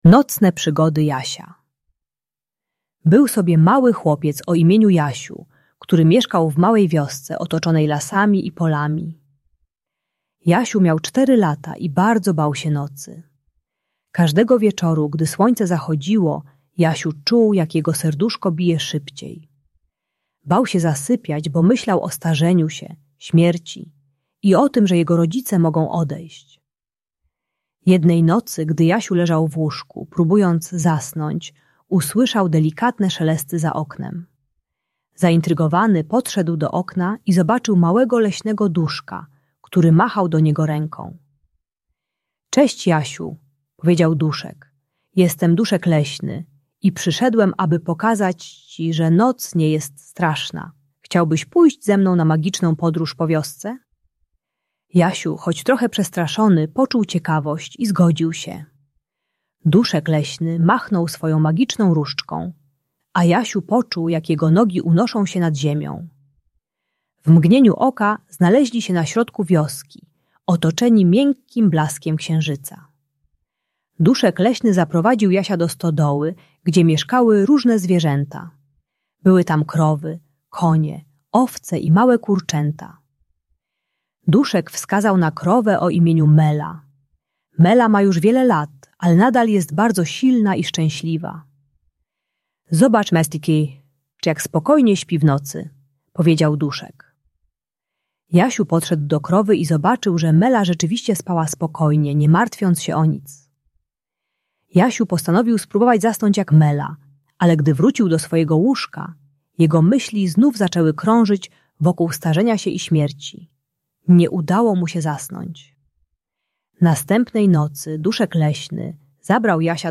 Nocne Przygody Jasia - Lęk wycofanie | Audiobajka